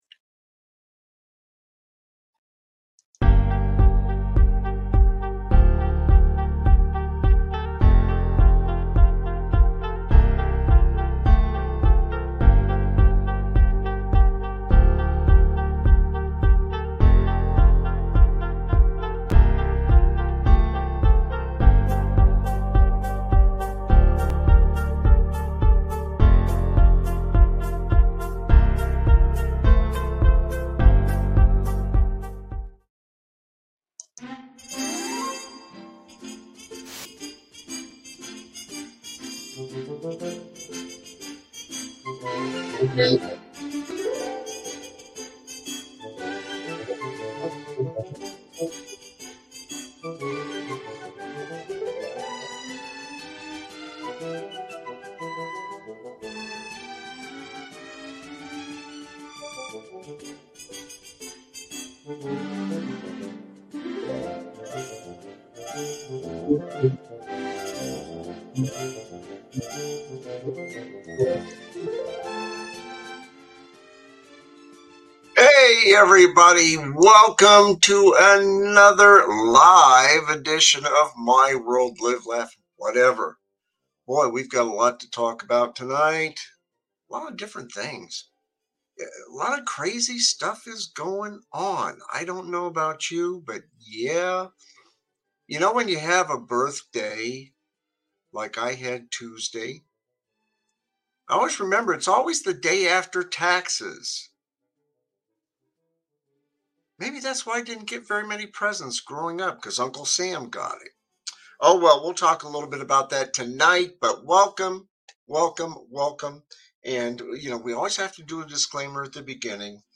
My World Live, Laff, Whatever is a satirical talk show that tackles the absurdities of life with a healthy dose of humor.